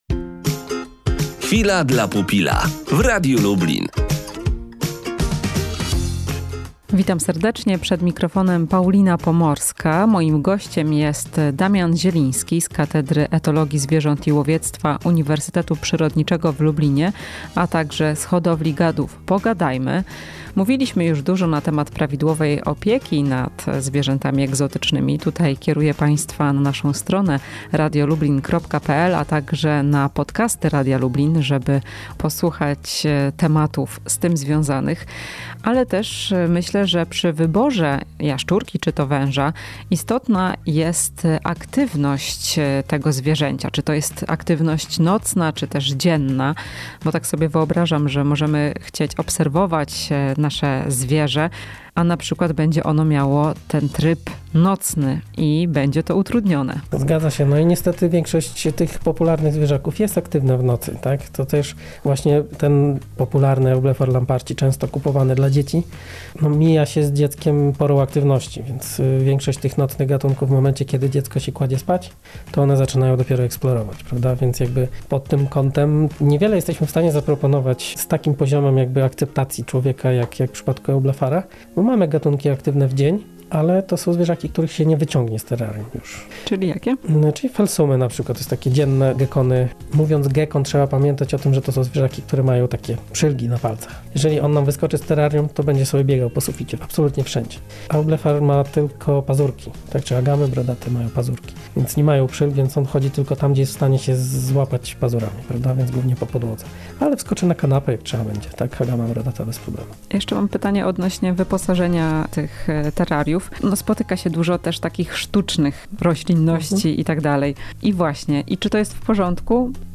O aktywności gadów. Rozmowa z dr. inż.